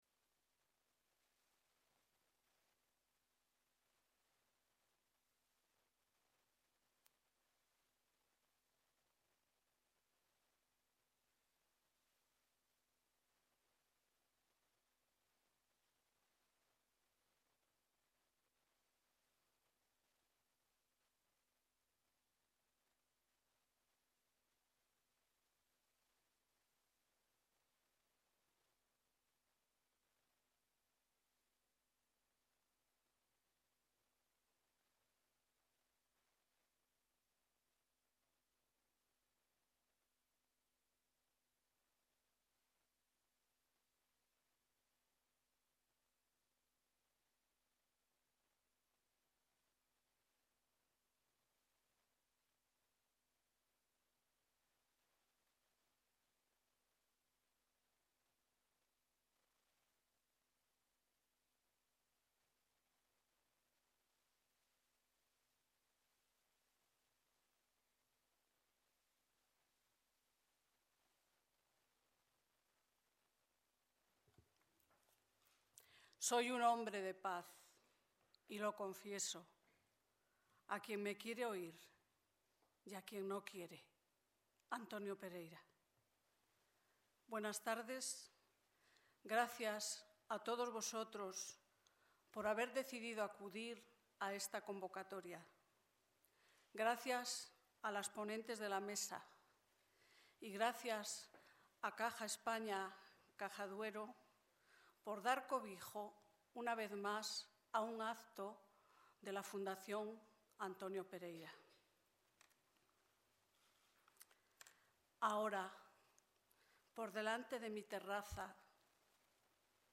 Conferencia organizada por la Fundación Antonio Pereira el 4 de noviembre de 2011